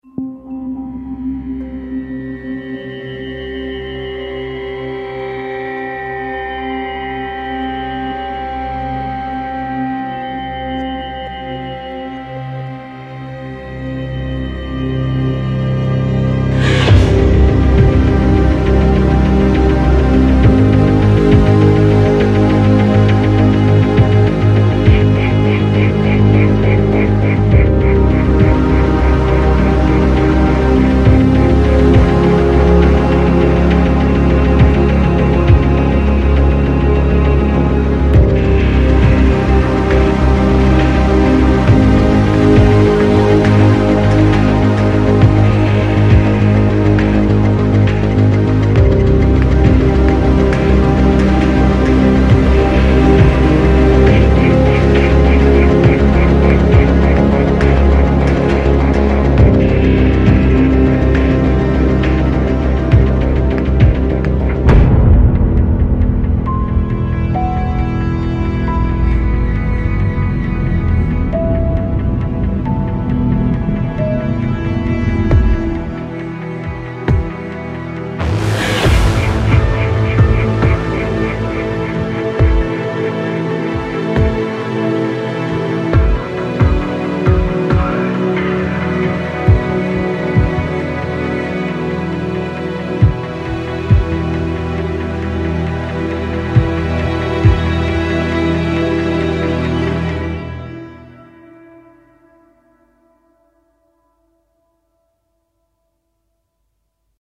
suspense - angoisse - peur - tueur - suspect